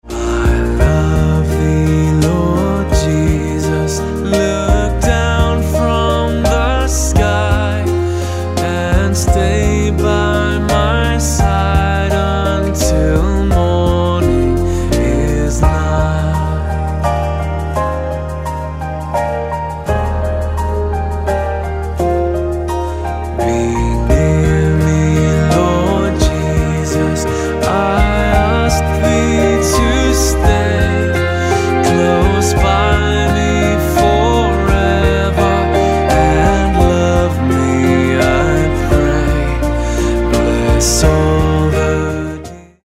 Eb